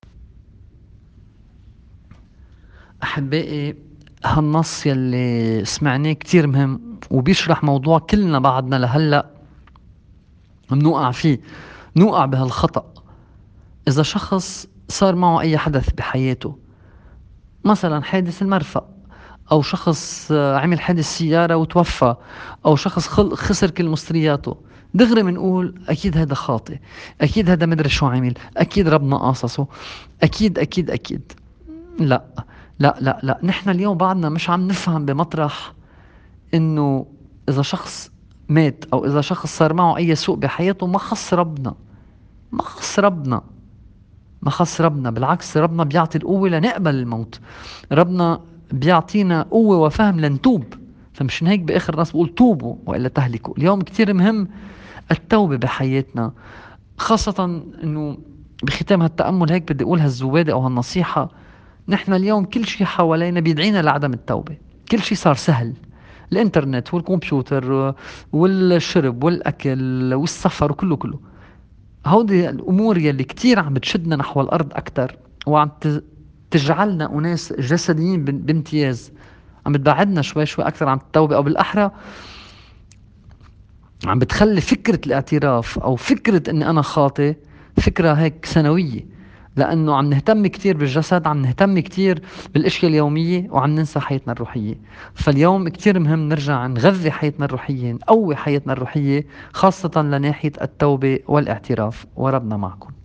تأمّل في إنجيل اليوم